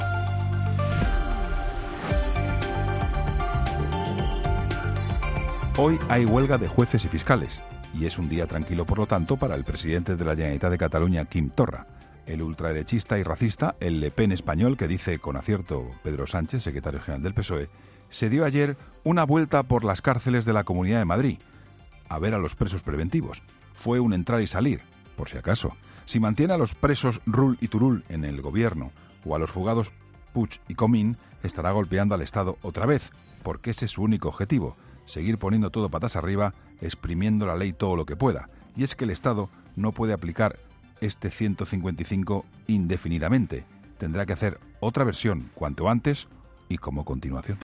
Escucha el comentario del director de 'La Linterna', Juan Pablo Colmenarejo, en 'Herrera en COPE'